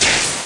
PixelPerfectionCE/assets/minecraft/sounds/mob/creeper/say4.ogg at mc116